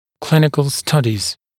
[‘klɪnɪkl ‘stʌdɪz][‘клиникл ‘стадиз]клинические исследования